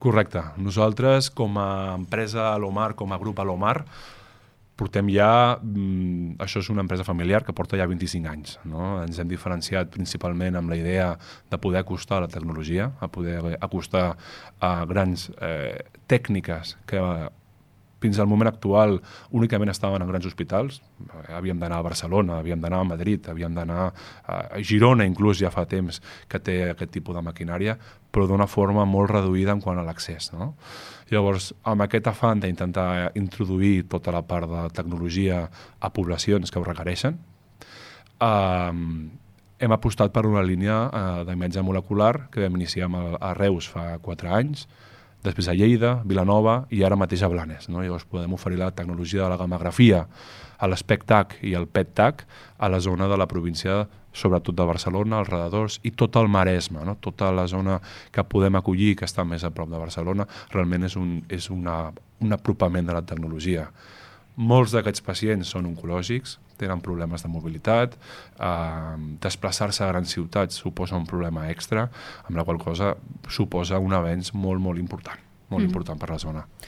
En una entrevista a Ràdio Capital